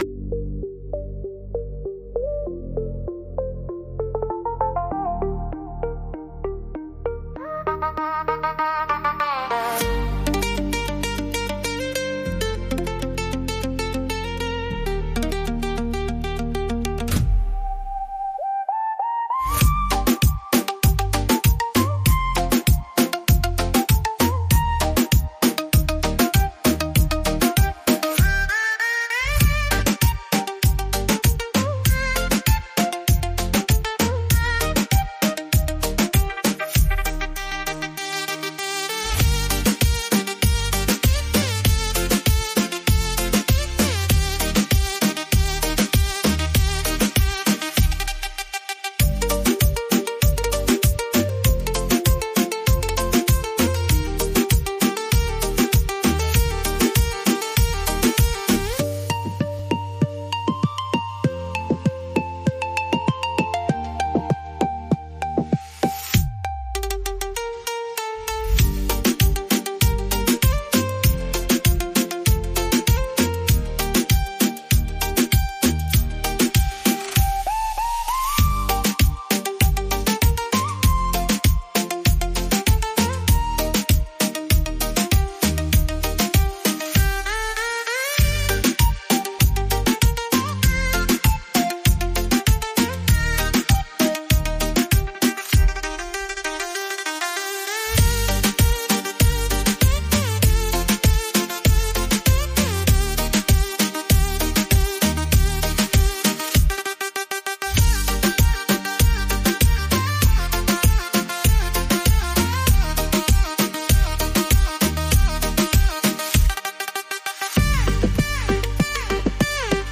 Beat Reggaeton Instrumental
Acapella e Cori Reggaeton Inclusi
• Mix e mastering di qualità studio
Gm